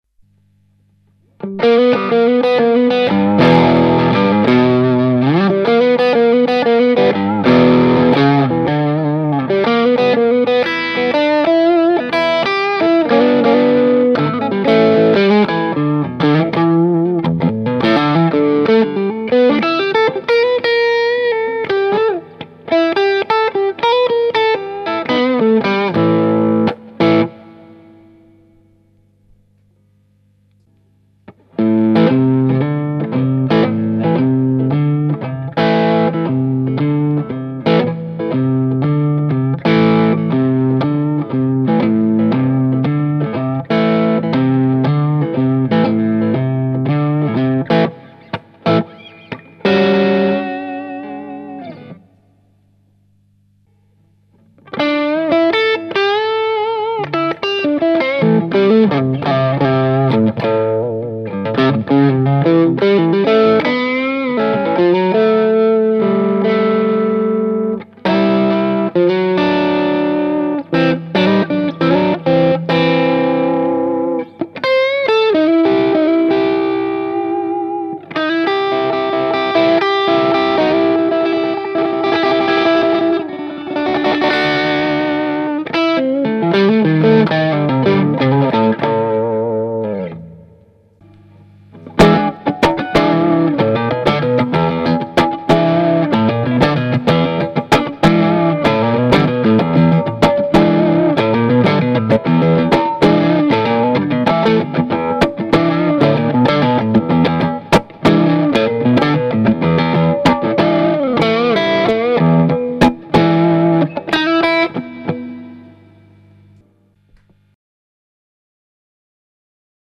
Voici une serie d'enregistrements qui ont été fait sur le Ten, l'ampli 10 watt de Pasqualiamps.
Le baffle utilisé est un "closed back" ave 2 HP Celestion G12-H. Les 3 premières plages ne contiennent aucun effet.
Plage 4 "Blues": Solo/Rythmique 'blues'/Solo/Rythmique 'Stevie Ray' "
Réglages Tactile Custom: Micro chevalet doubleTone 100 % - Volume 100 %
Réglage Pasqualiamps Ten: Tone 5 - Volume 1
Réglages des effets: Tube Screamer (Drive: 8 Tone 2 Level 9)